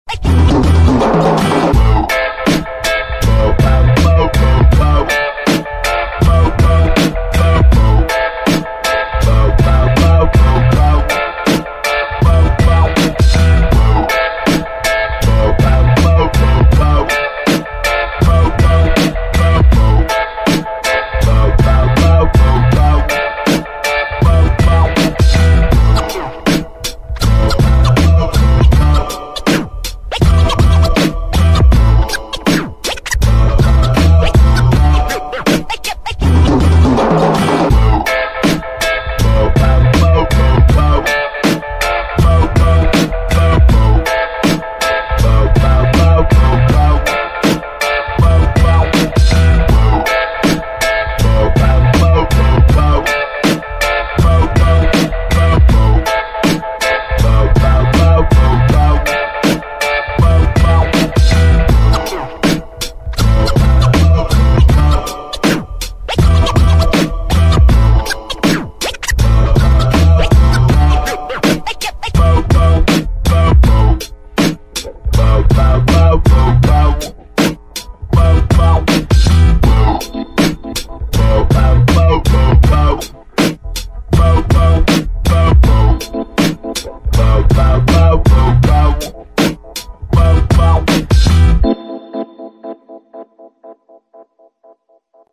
• Качество: 320, Stereo
скретч